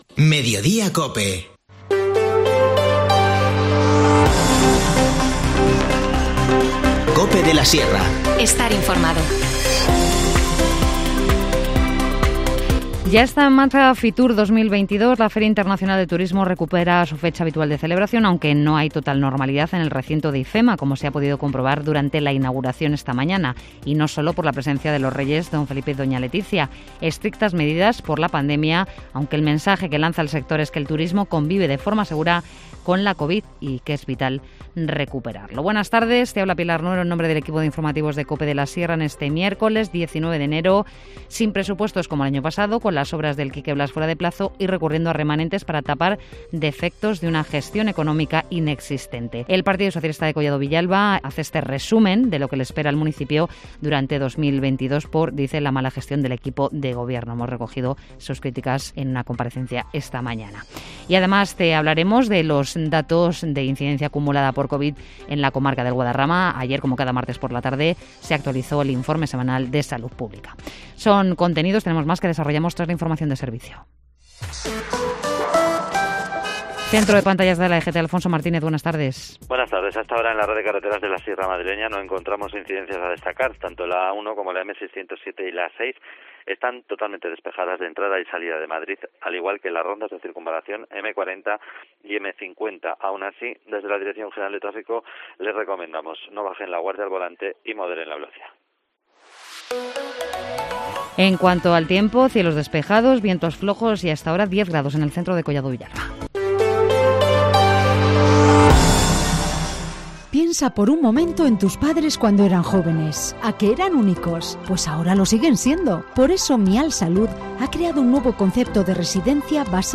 Informativo Mediodía 19 enero